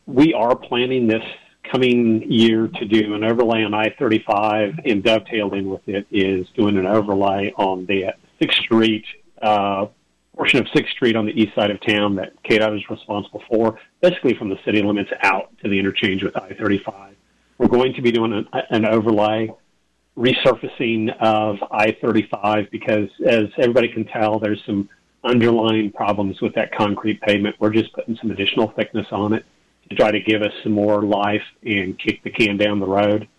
During an interview on KVOE’s Morning Show Tuesday